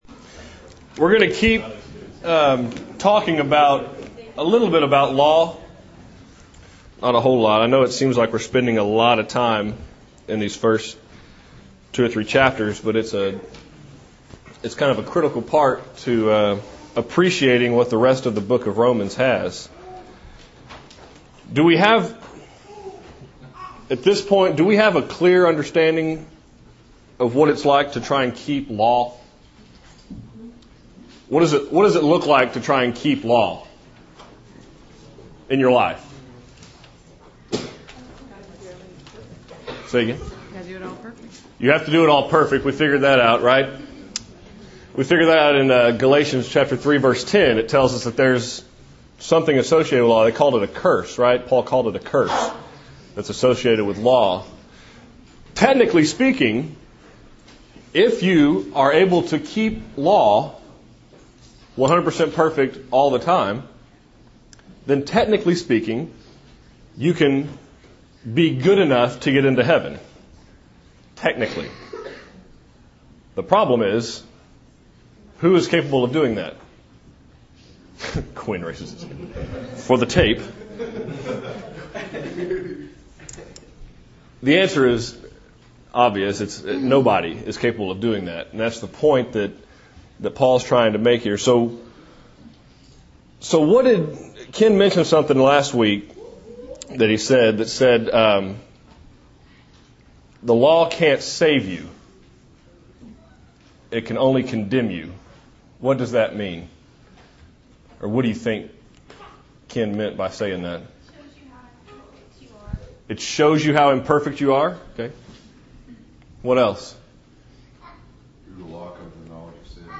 Law and Faith (III) (8 of 24) – Bible Lesson Recording
Sunday AM Bible Class